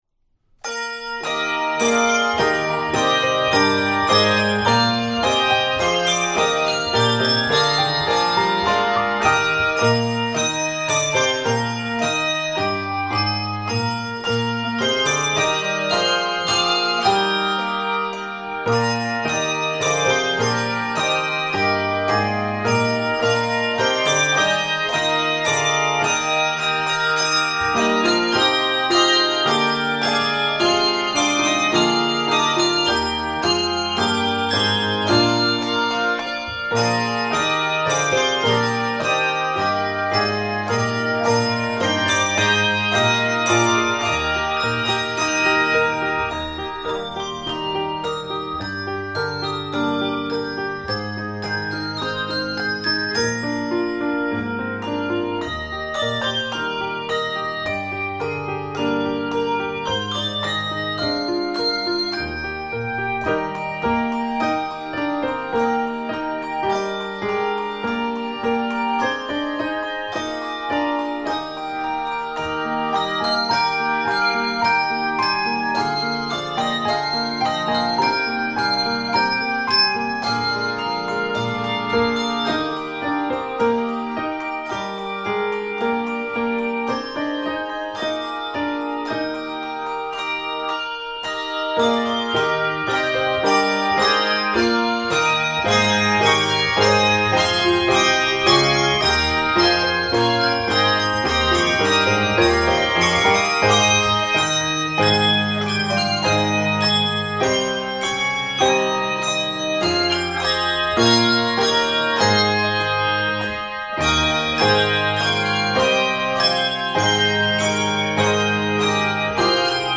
Keys of Eb Major and Ab Major.